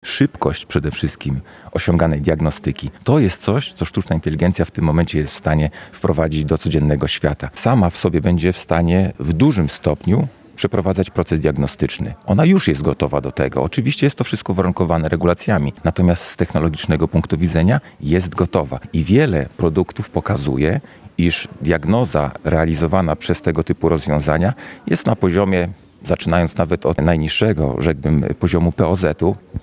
Sztuczna inteligencja w diagnostyce. Konferencja w bialskiej AWF